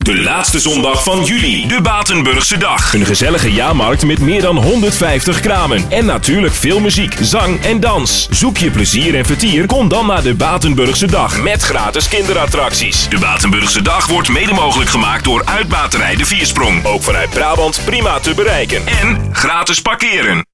Radiospot bij Radio NL - Batenburgse Dag